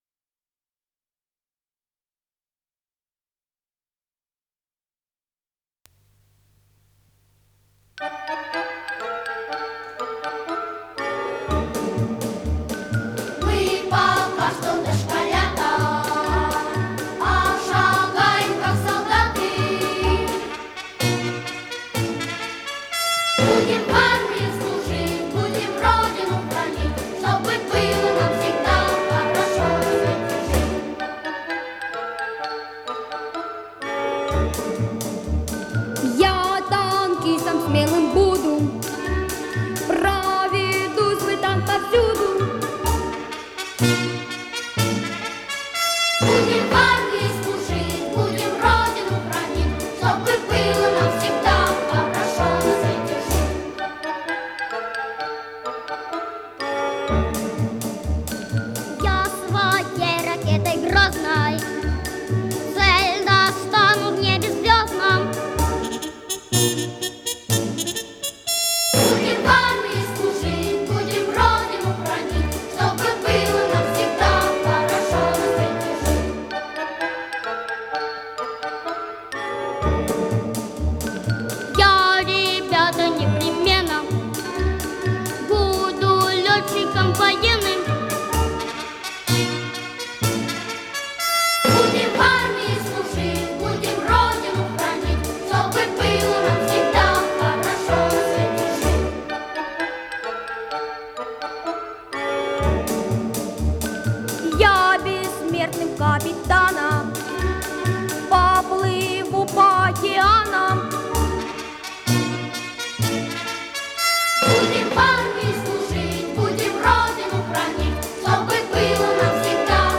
АккомпаниментИнструментальный ансамбль
ВариантДубль моно